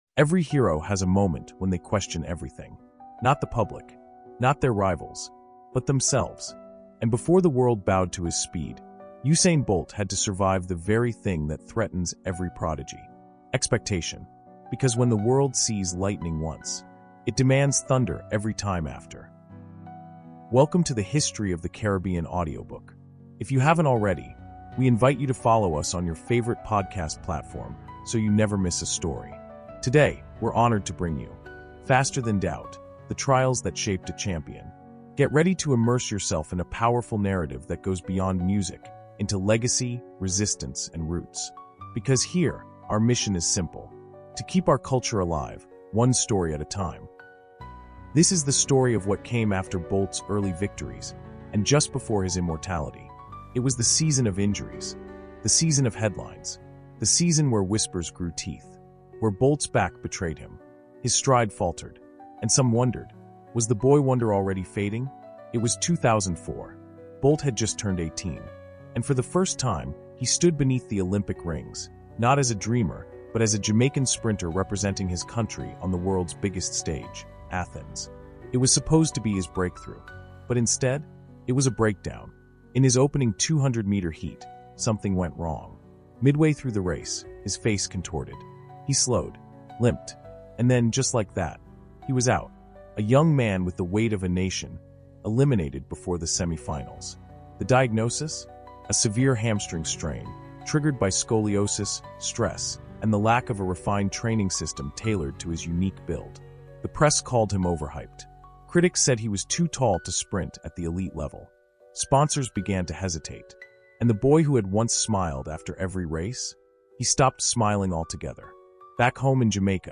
Through raw narration and emotional depth, we examine how self-doubt became Bolt’s fiercest competitor—and how he outran it, step by painful step.